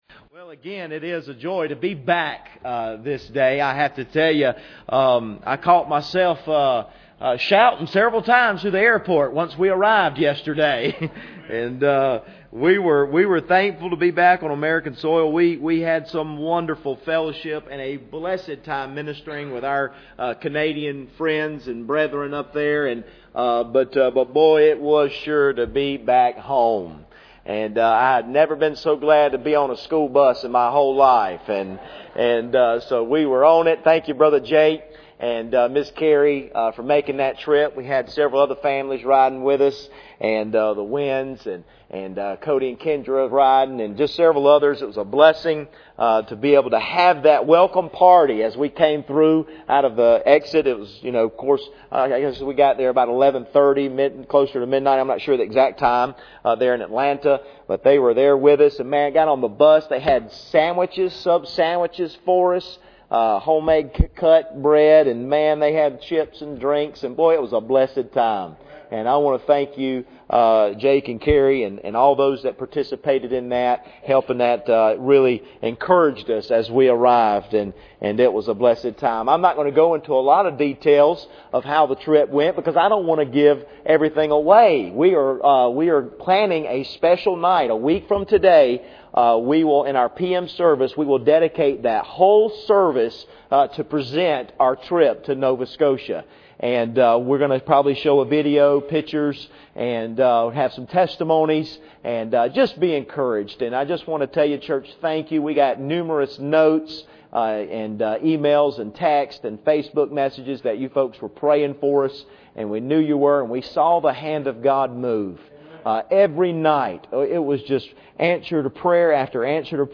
Passage: 1 Corinthians 16:15-18 Service Type: Sunday Morning